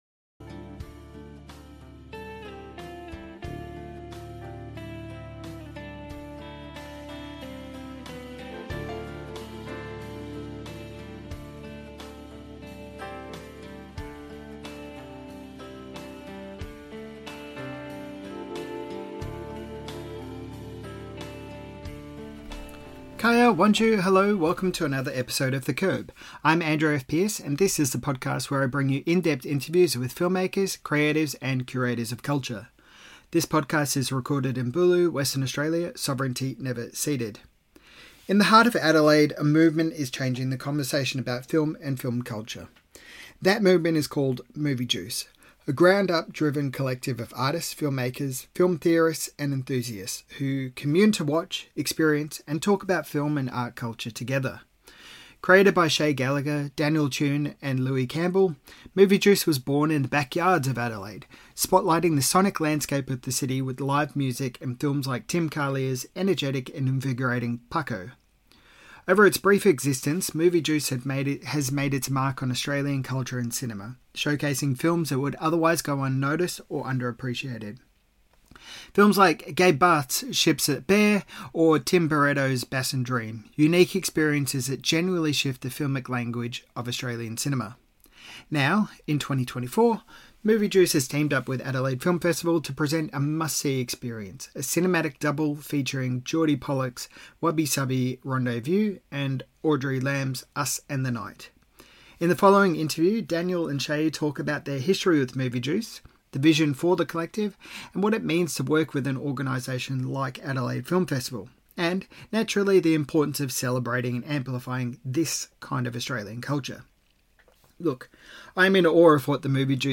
Adelaide Film Festival Critics Round Up Discussion - The Curb | Film and Culture
The following discussion sees us traipse along a path of the highs and the lows of the Adelaide Film Festival, with each critic highlighting some of their favourite films for the festival, and some of the films that have left them wanting.